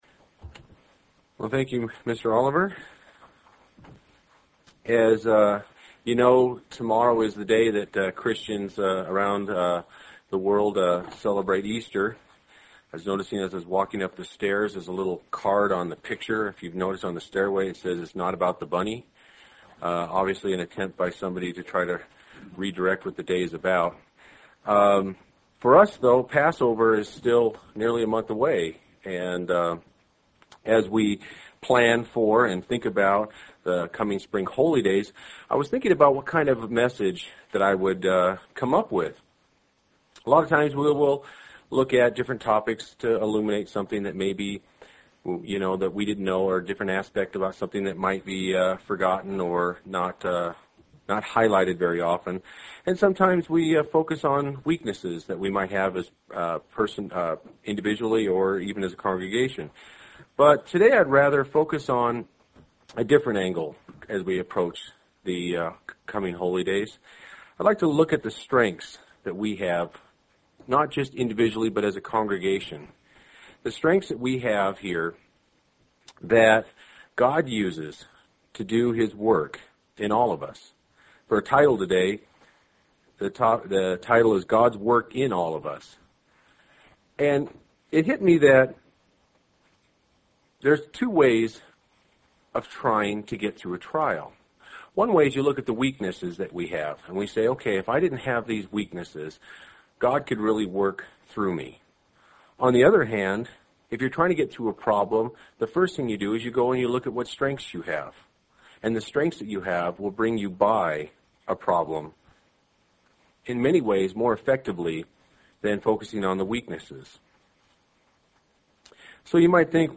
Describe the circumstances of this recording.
Given in Central Oregon